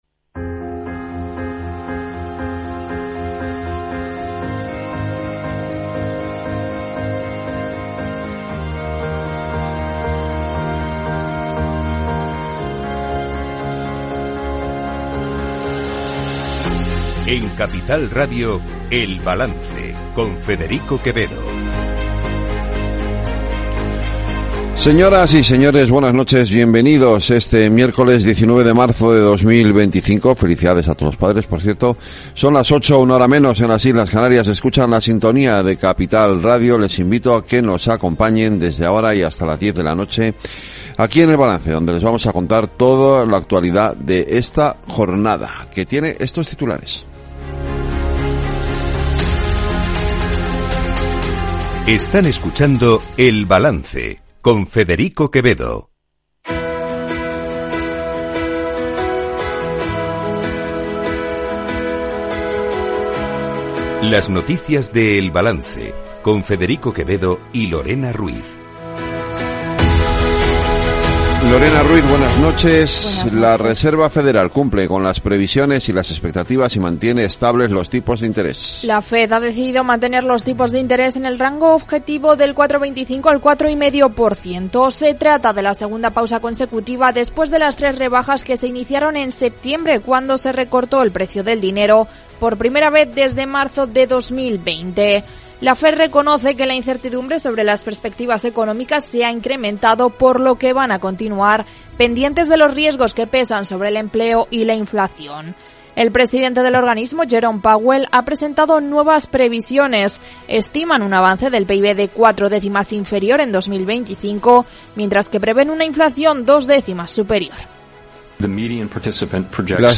El Balance es el programa informativo nocturno de Capital Radio, una manera distinta, sosegada y reflexiva de analizar la actualidad política y económica